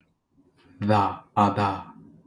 Voiced linguolabial tap ɾ̼ (Voiced linguolabial tap) Voiced linguolabial tap.wav (
Voiced_linguolabial_tap.wav